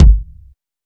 Kicks
KICK.9.NEPT.wav